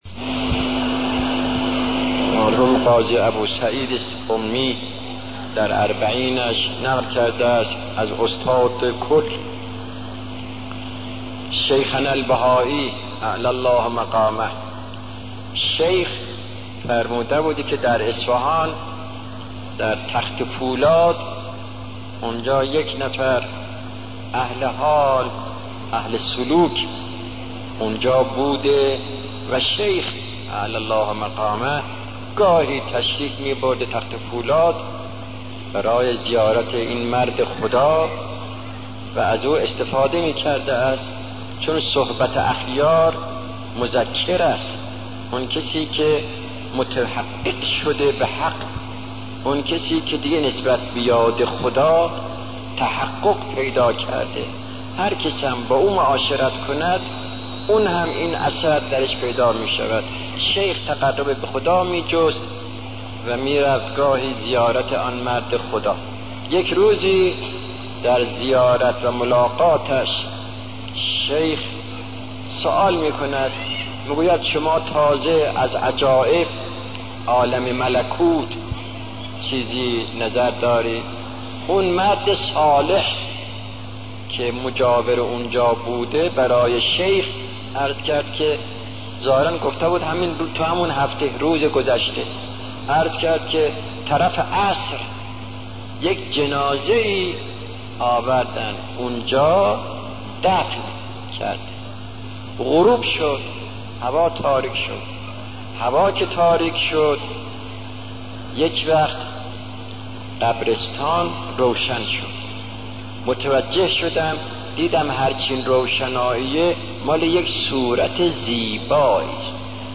سخنرانی شهید آیت الله دستغیب در مورد کیفیت اعمال